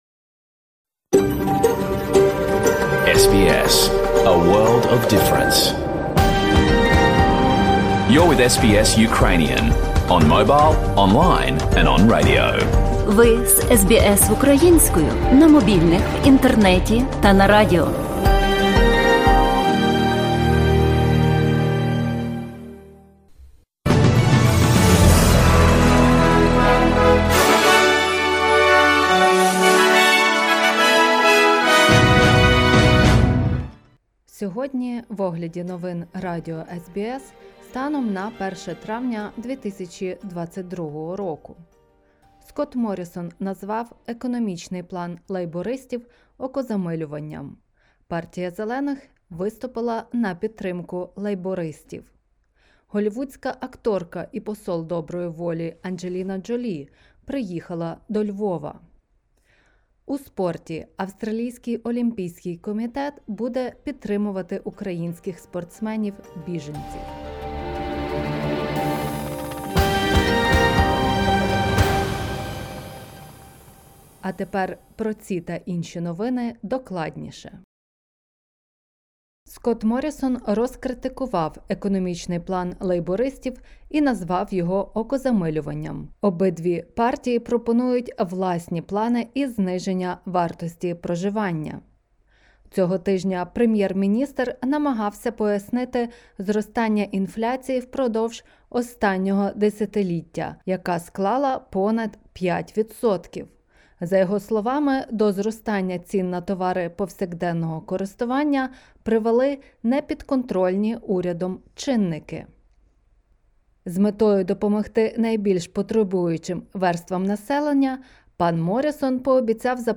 Бюлетень SBS новин українською мовою.